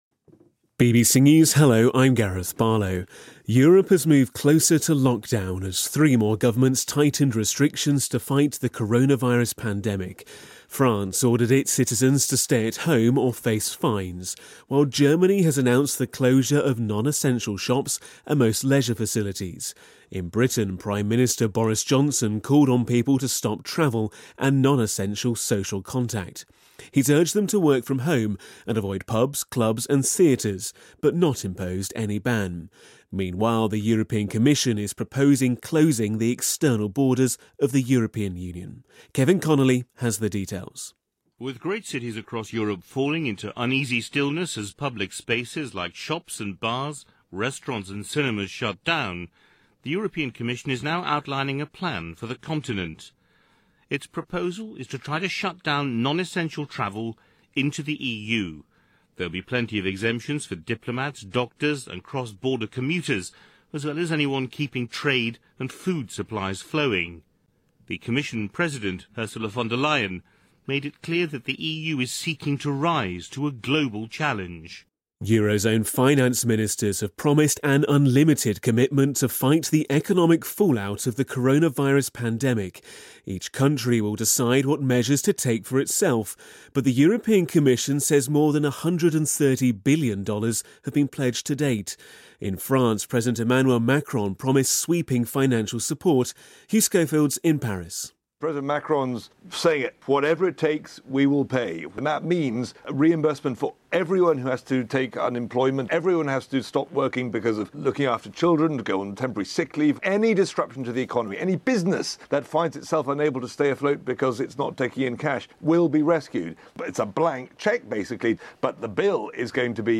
News
英音听力讲解:欧洲各国开始封锁抗击新冠疫情